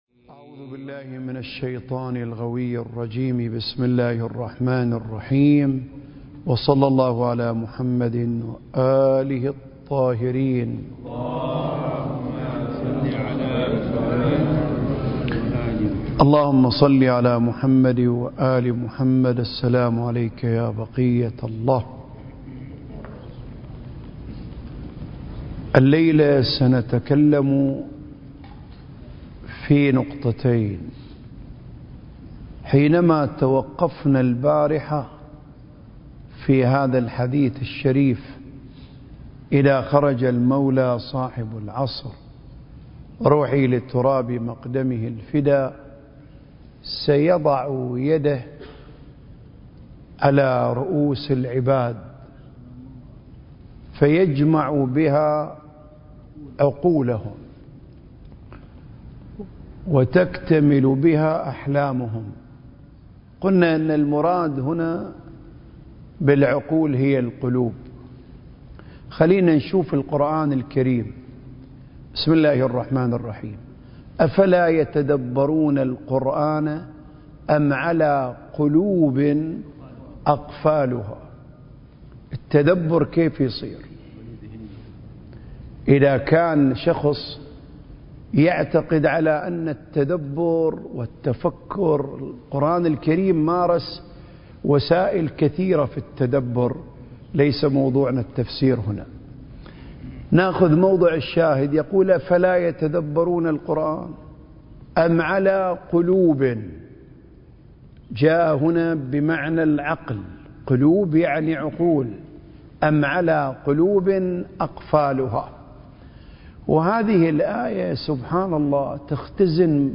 سلسلة محاضرات: آفاق المعرفة المهدوية (4) المكان: الأوقاف الجعفرية بالشارقة التاريخ: 2023